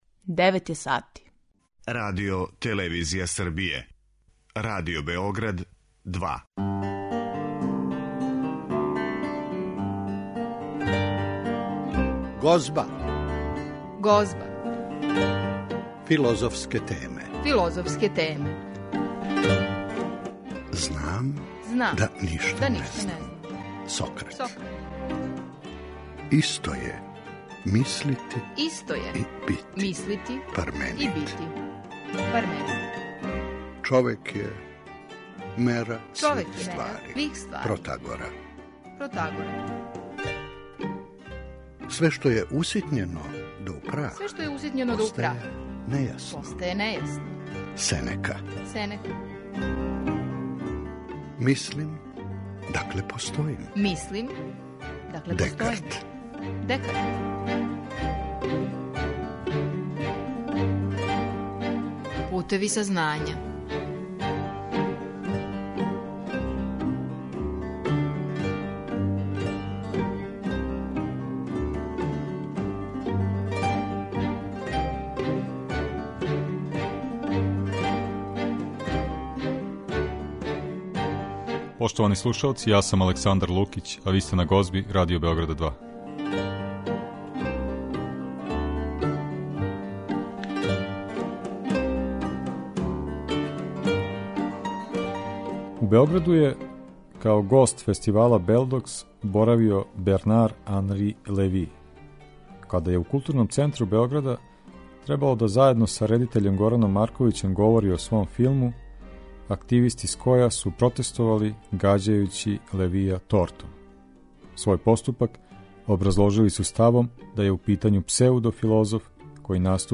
разговарамо